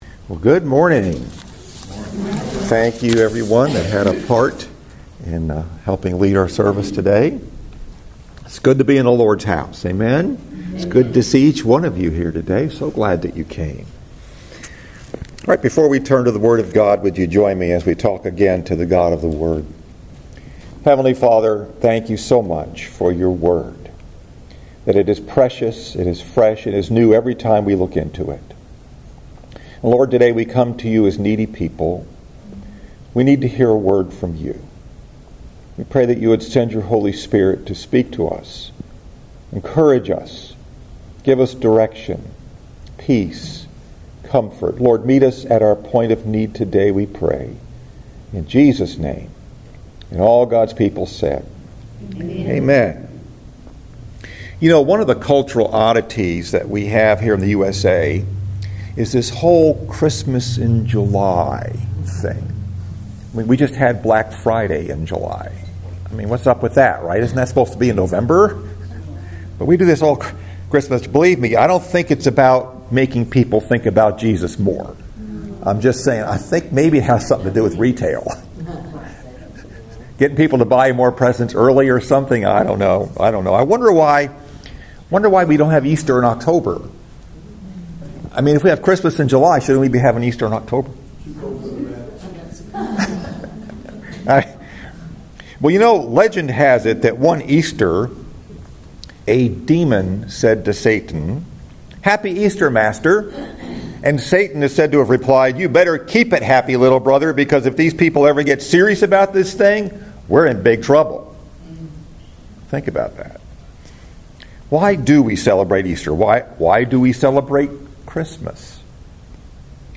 Message: “What if It’s True?” Scripture: 2 Timothy 4